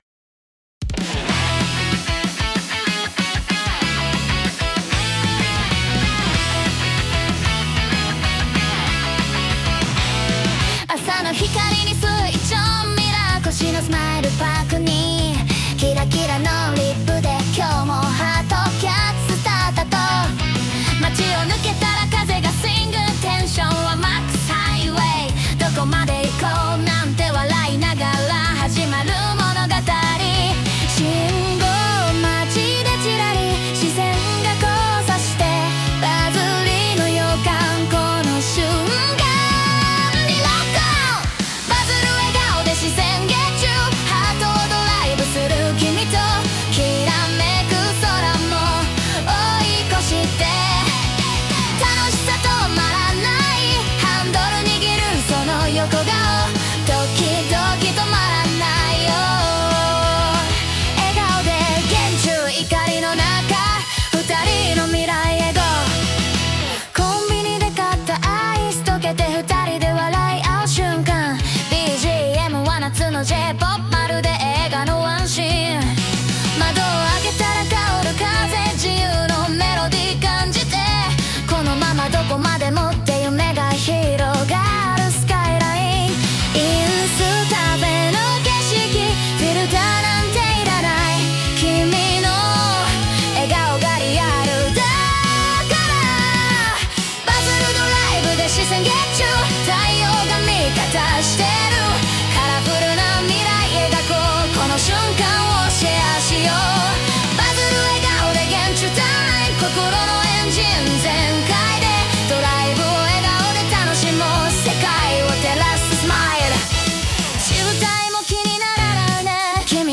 SNSで話題になること間違いなしのキュートなポップナンバー！